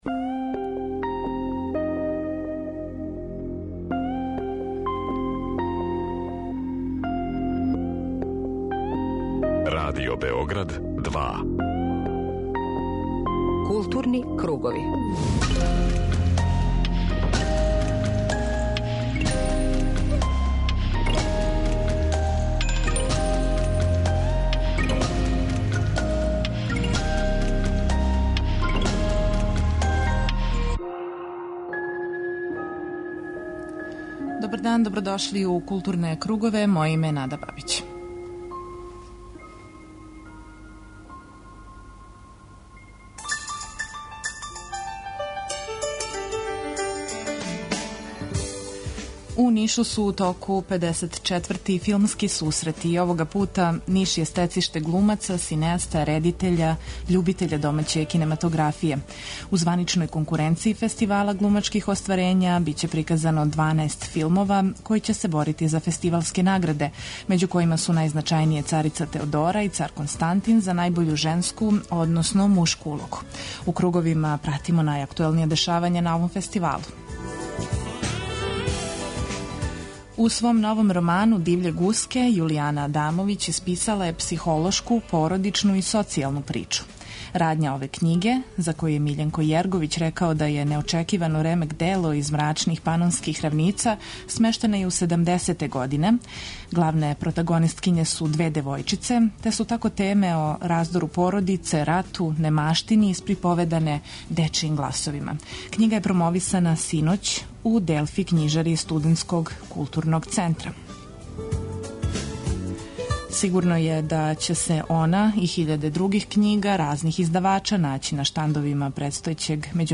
Дневни магазин културе Радио Београда 2